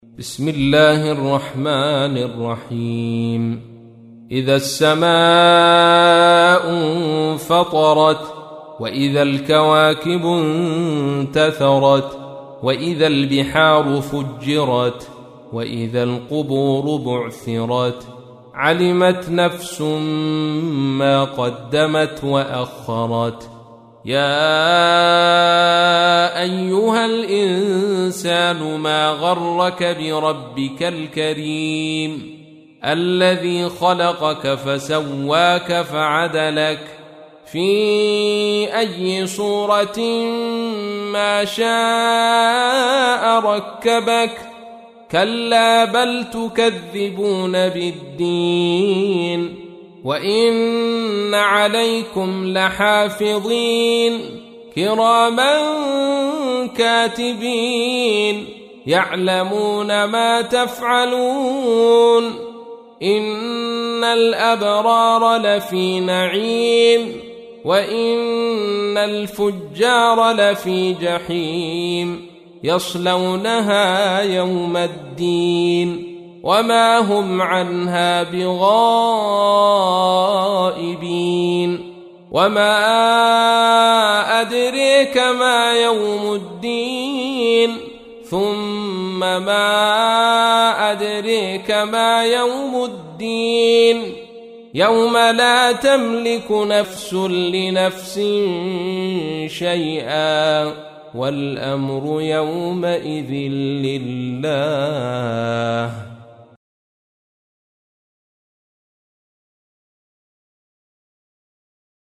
تحميل : 82. سورة الانفطار / القارئ عبد الرشيد صوفي / القرآن الكريم / موقع يا حسين